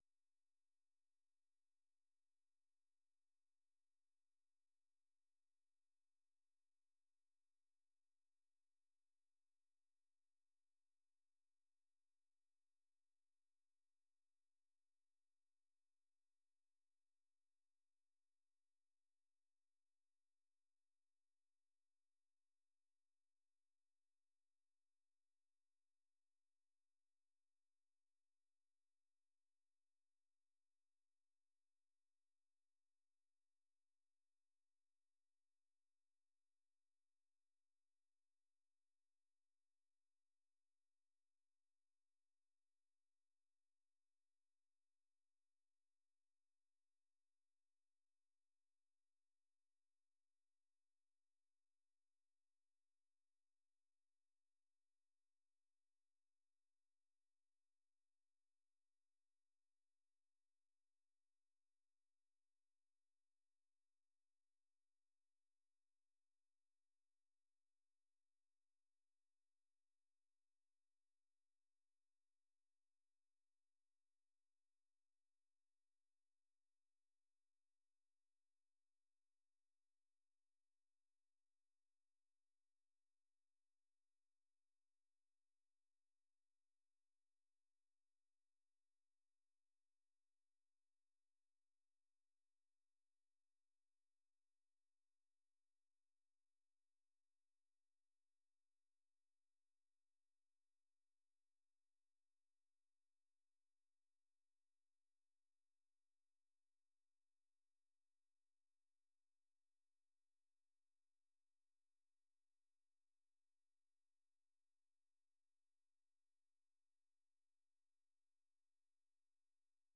VOA 한국어 아침 뉴스 프로그램 '워싱턴 뉴스 광장'입니다.